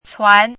chinese-voice - 汉字语音库
cuan2.mp3